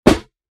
DHL SNARE 1.mp3